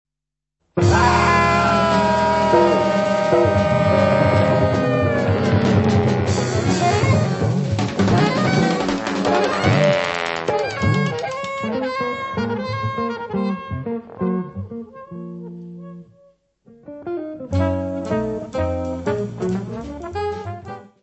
guitarra
saxofone alto e saxofone soprano
trombone
bateria
contrabaixo.
Área:  Jazz / Blues